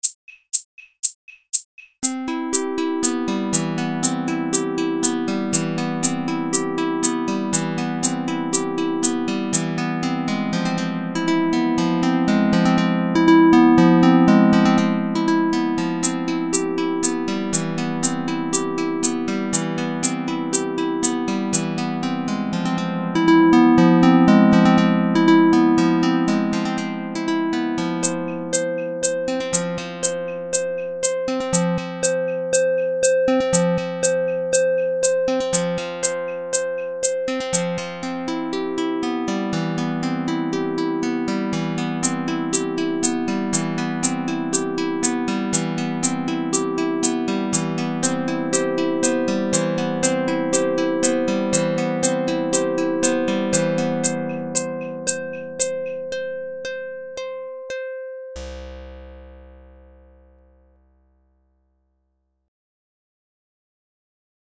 Hope Always - written for my very special fiancée; I think the song has a very uplifting sound to it.